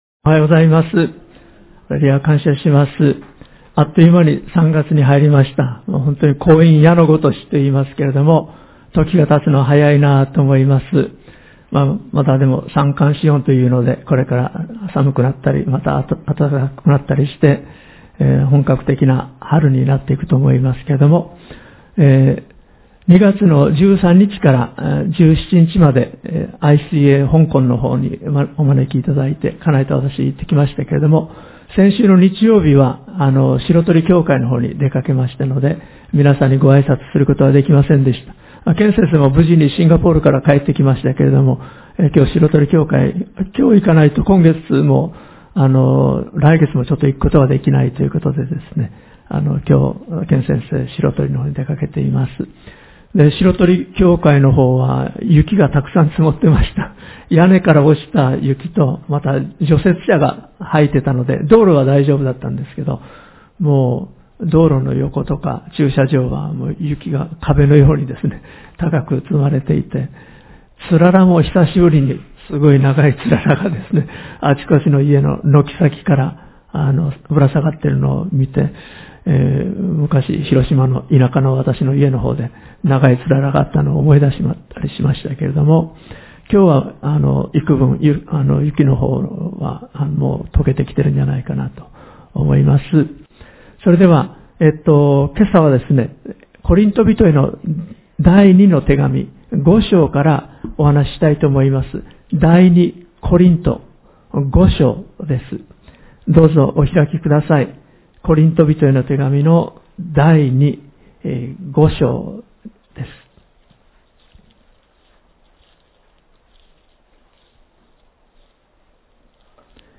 岐阜純福音教会 礼拝メッセージ